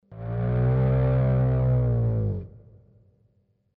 The cow   mp3 04'' 59 Kb
cow.mp3